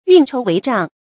運籌帷帳 注音： ㄧㄨㄣˋ ㄔㄡˊ ㄨㄟˊ ㄓㄤˋ 讀音讀法： 意思解釋： 同「運籌帷幄」。